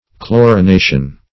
Chlorination \Chlo`ri*na"tion\, n.